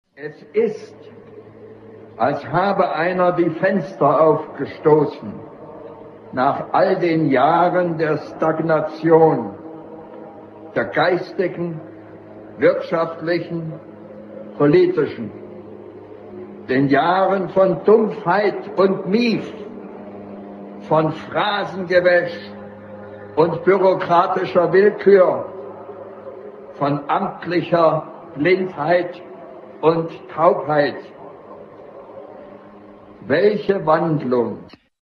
Die Stimmen der DDR...
Welche Wandlung (Berliner Demo - 4. November 1989)